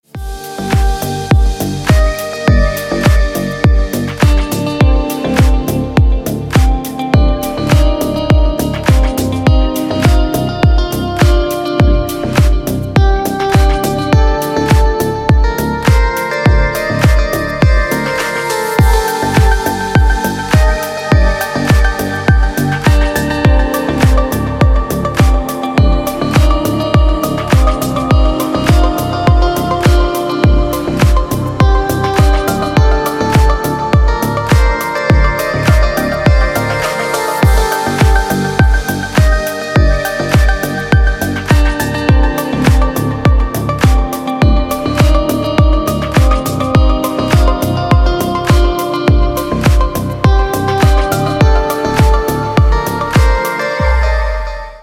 Спокойная музыка на гаджет
Спокойные рингтоны